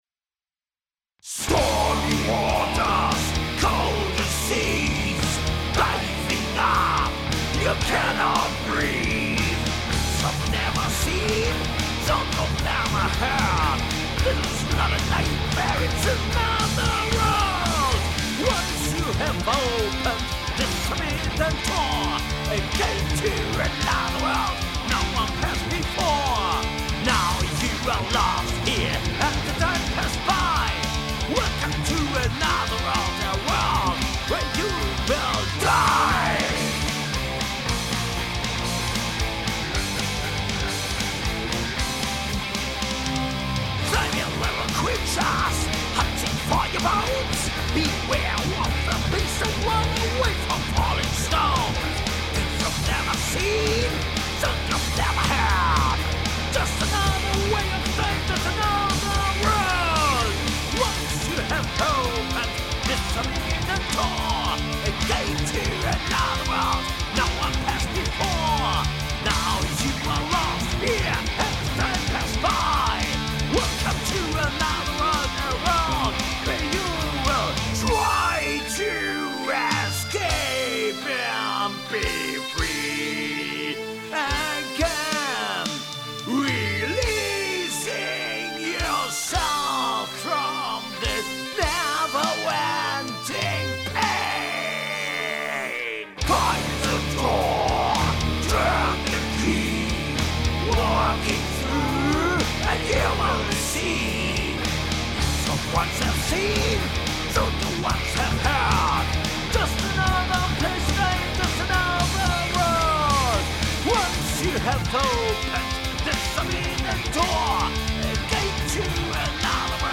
Metal aus Plettenberg!
HEAVY METAL
Bass
Gitarre
Keys
Gesang
Drums